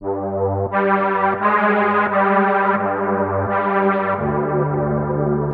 Yark Strings 02.wav